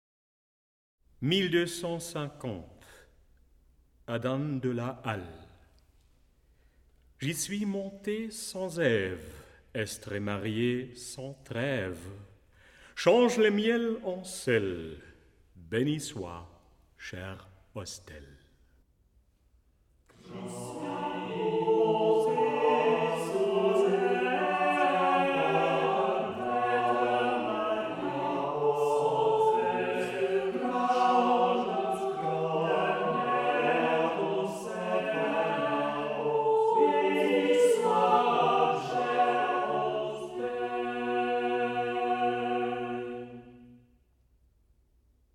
27 autogrammes in the style of the old masters